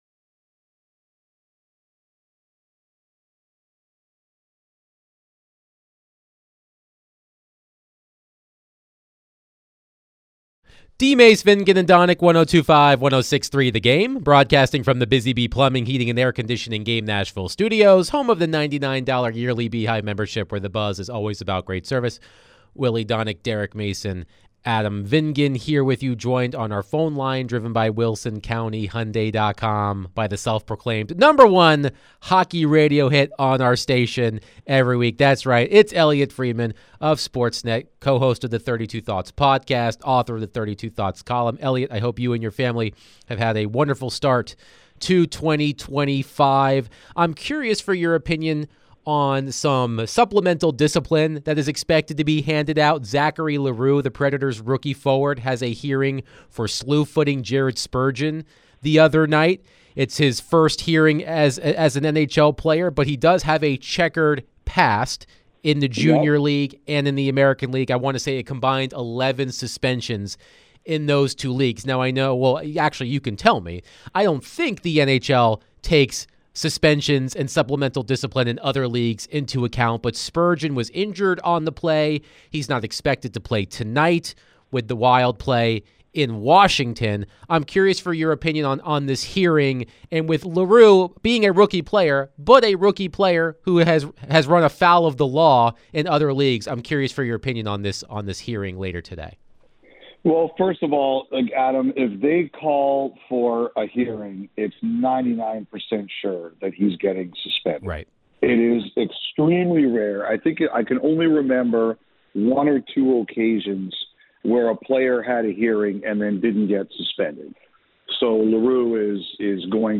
Sports Net NHL Insider Elliotte Friedman joined DVD to discuss all things around the NHL, Nashville Predators and more